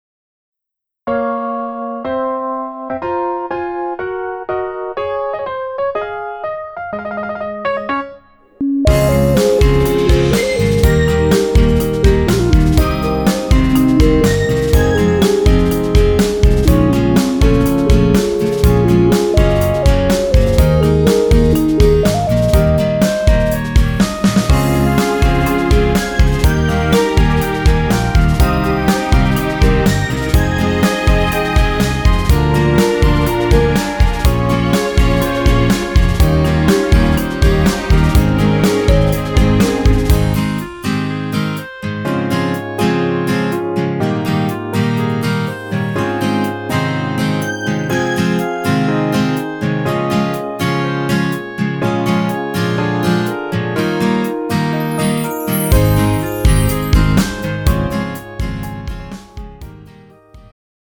음정 남자키
장르 축가 구분 Pro MR